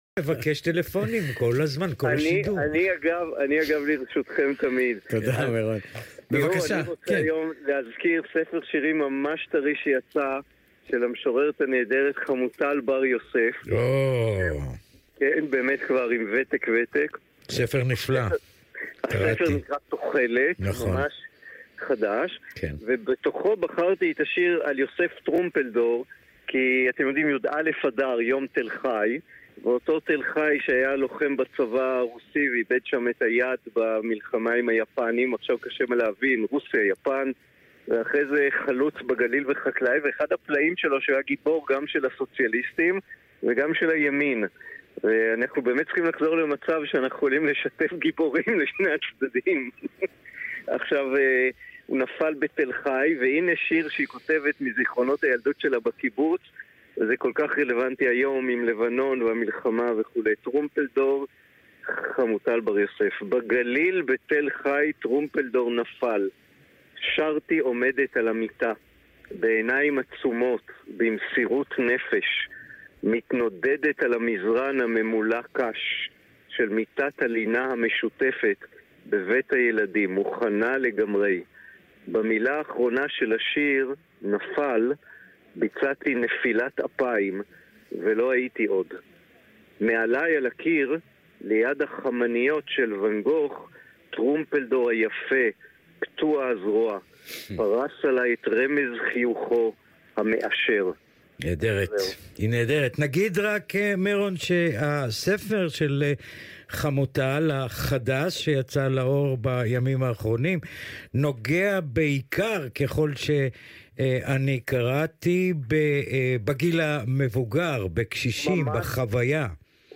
ראיון בגל"צ